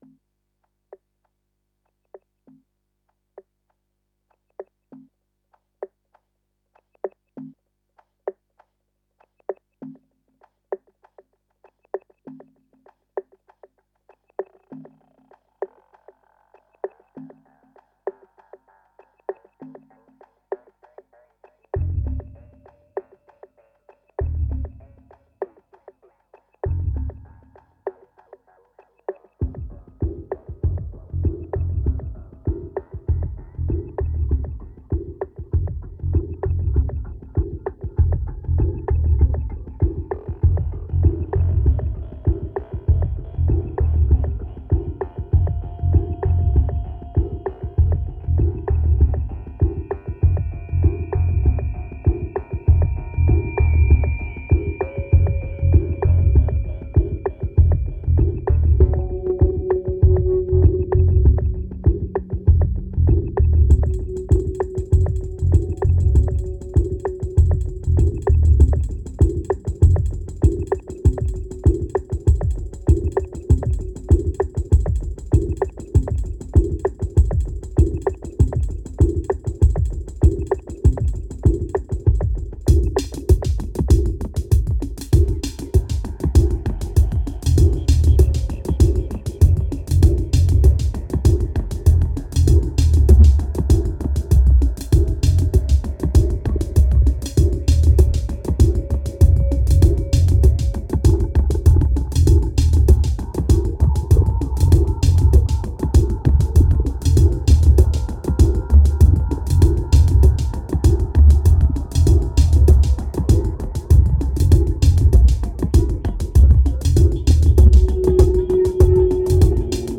2623📈 - 67%🤔 - 98BPM🔊 - 2011-08-06📅 - 158🌟